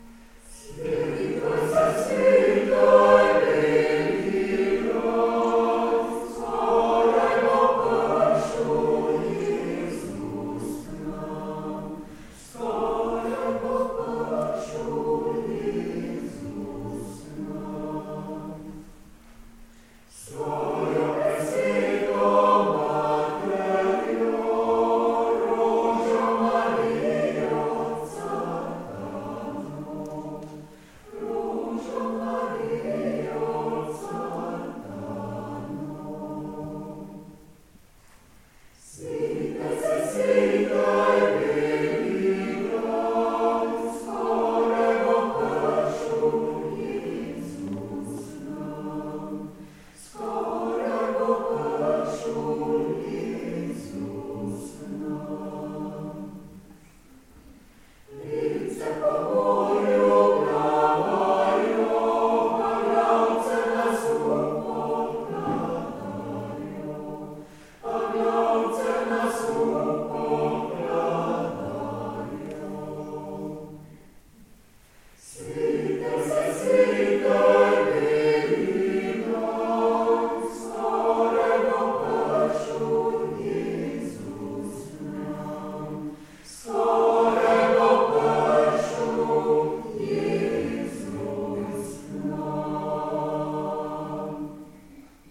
Nastop v Thal – Gradec, december 2014
Na koncertu smo se v sodelovanju z zborom Singkreis Thal iz Gradca predstavili z naslednjimi pesmimi: